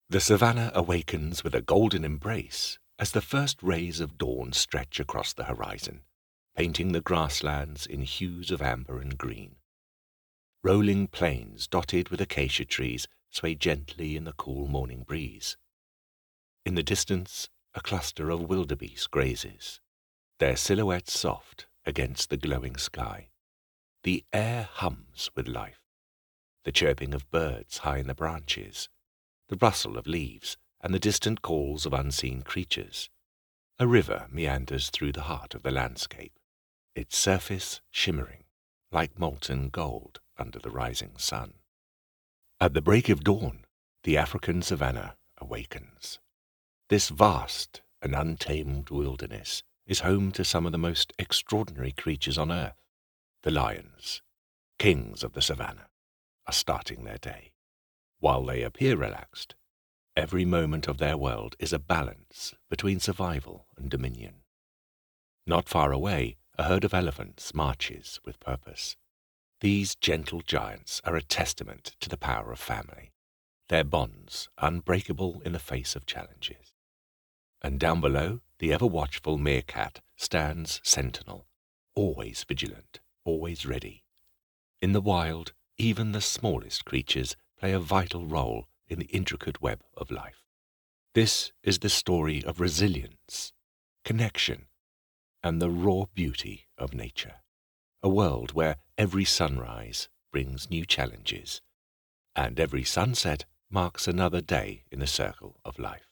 Wildlife Documentary
Received Pronunciation, London, Cockney, West Country, British Military
Wildlife Documentary Narration.mp3